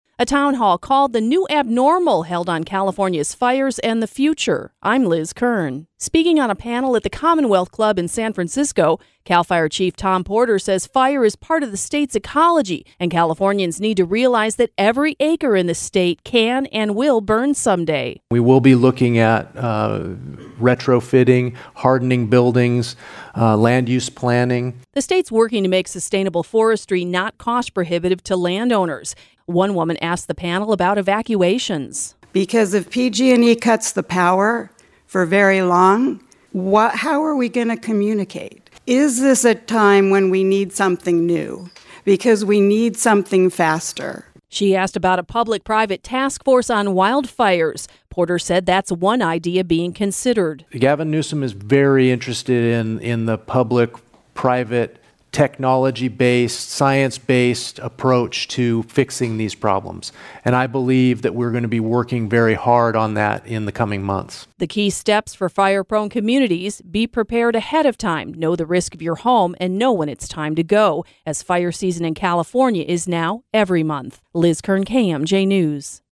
FRESNO, CA (KMJ) – Leading wildfire experts speak a town hall Tuesday on California’s fires and what can be done in the short and long term to prepare for them.